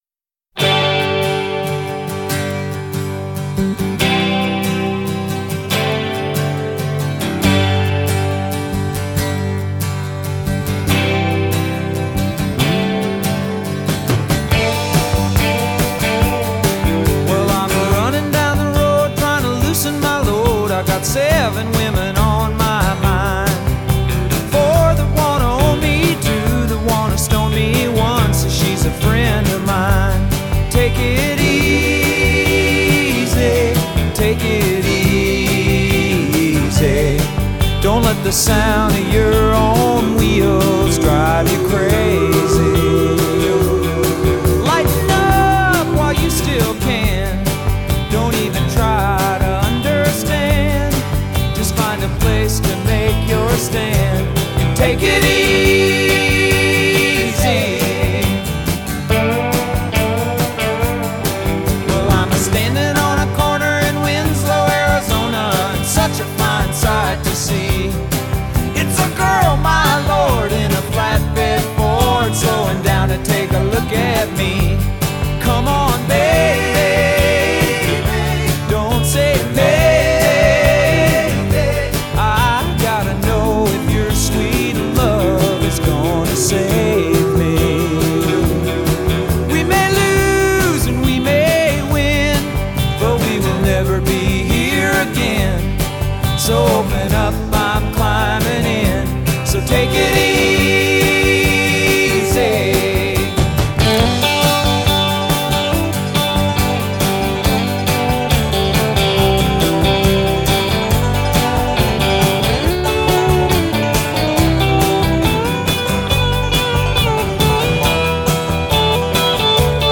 рок-музыка